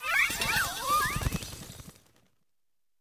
Cri de Cléopsytra dans Pokémon Écarlate et Violet.